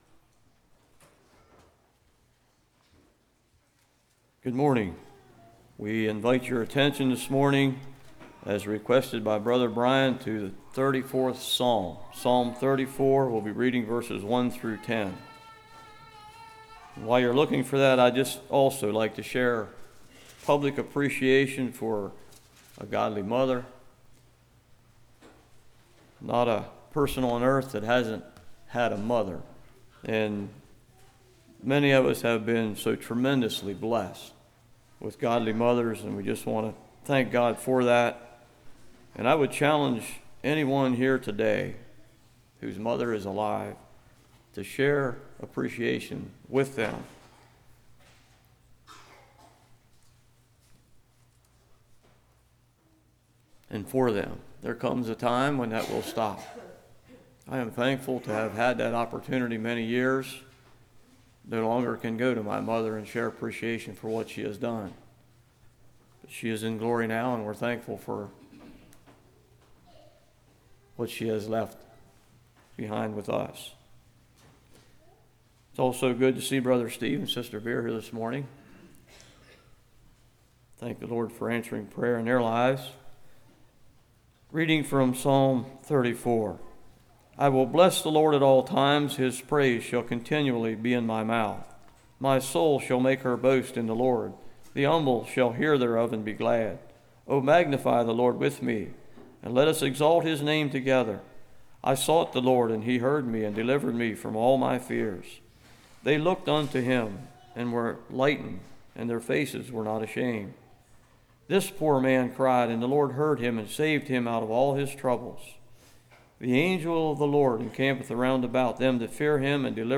Service Type: Morning